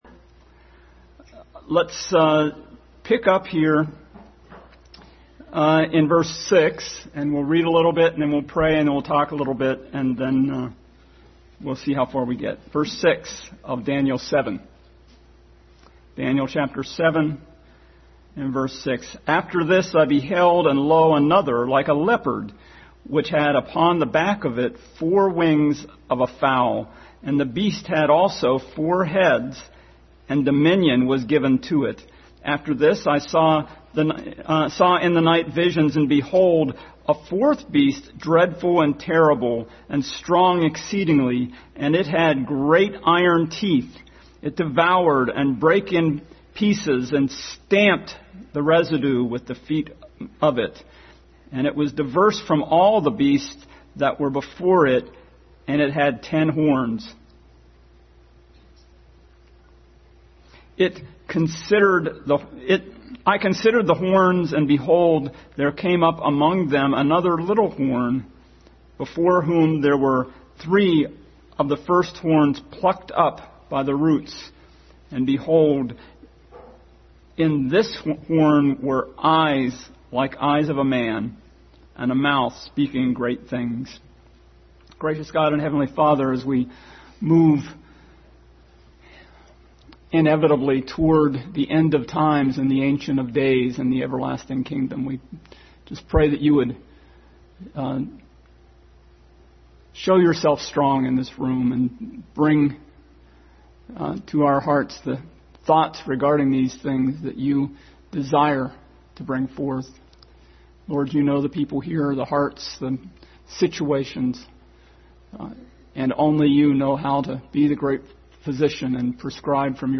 Daniel 7:6-14 Passage: Daniel 7:6-14, Revelation 4:1-11, 5:1-14 Service Type: Family Bible Hour